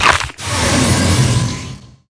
FISH SOUNDS:
fishuse.wav